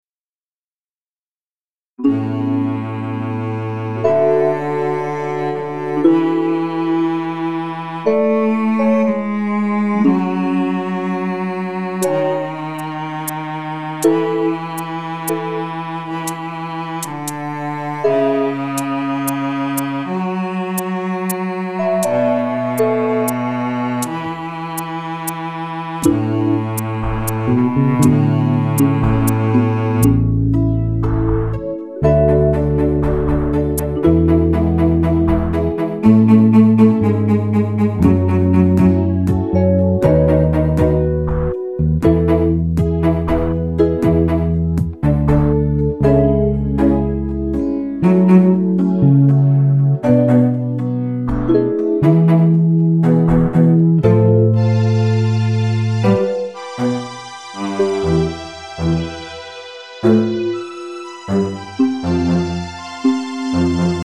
チェロの音が目立つアレンジですね。
楽器数が少なくて、気軽に聞ける癒し系な曲を目指してみました。
途中から入るベースの音は自分で弾いて録音してます。